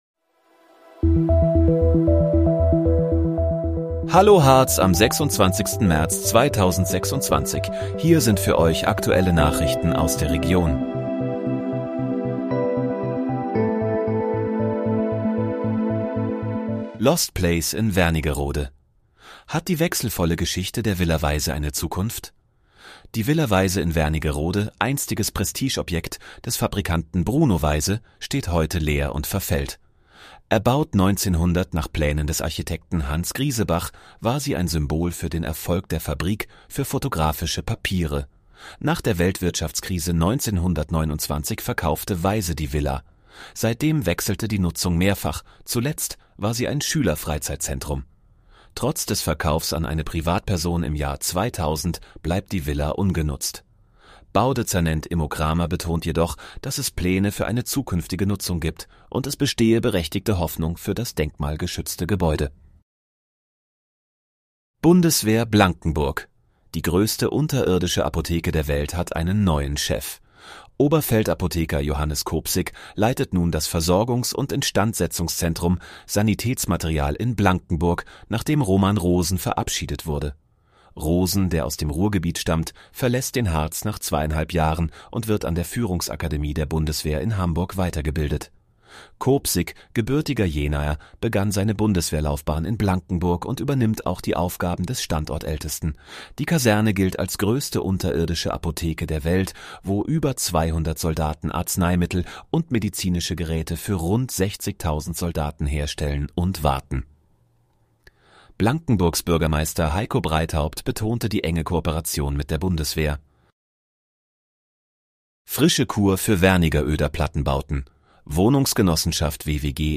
Hallo, Harz: Aktuelle Nachrichten vom 26.03.2026, erstellt mit KI-Unterstützung